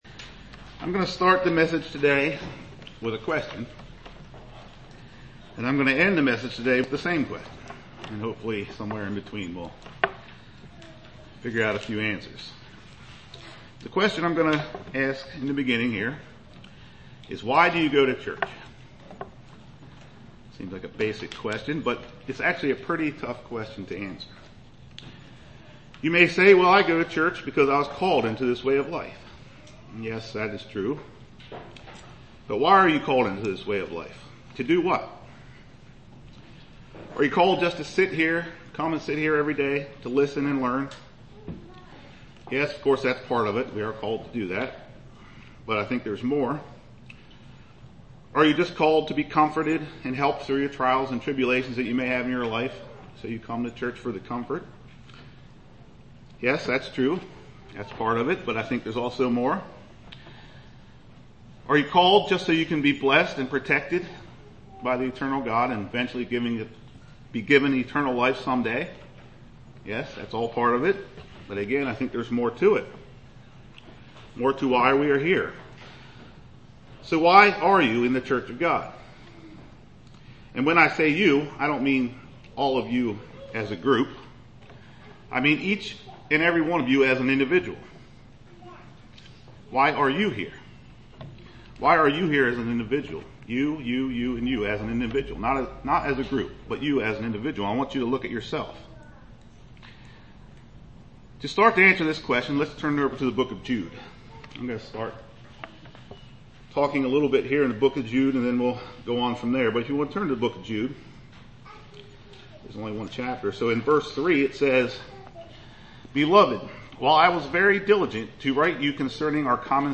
Given in Lewistown, PA York, PA
UCG Sermon Studying the bible?